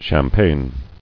[Cham·paign]